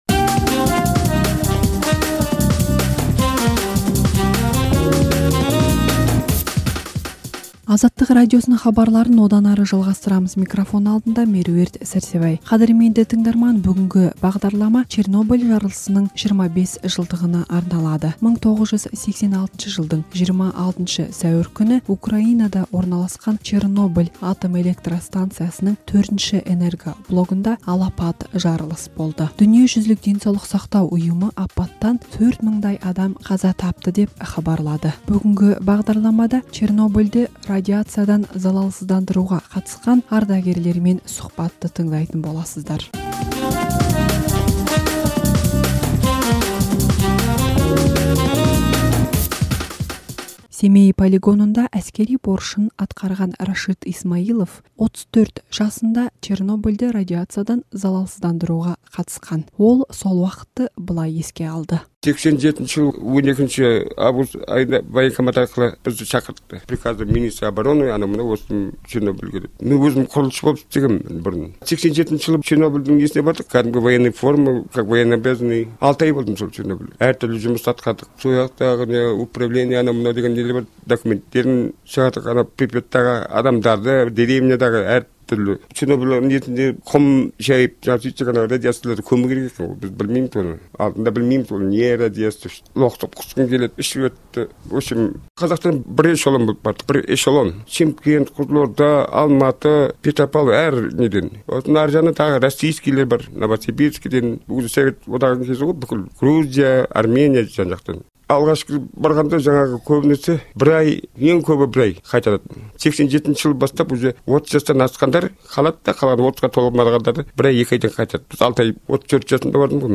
Чернобыль апаты орнында болған қазақстандықтармен сұқбатты тыңдаңыз